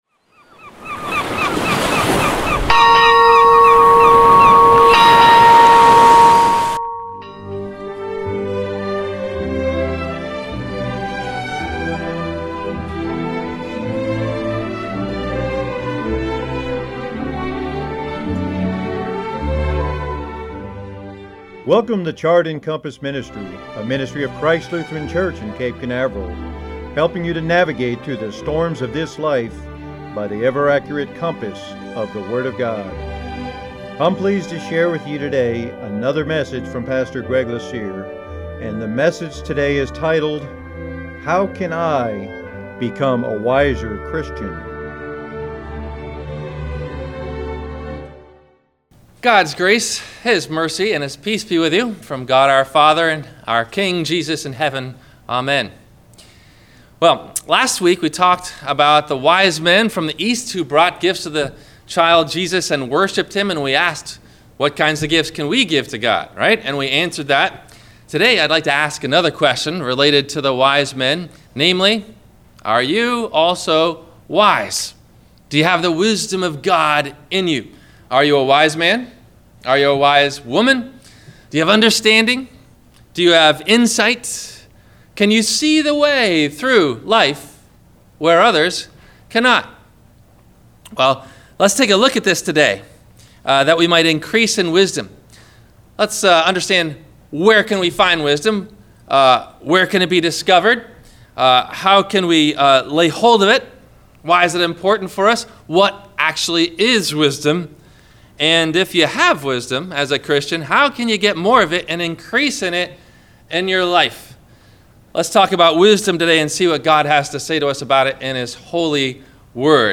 How Can I Become a Wiser Christian? – WMIE Radio Sermon – April 27 2015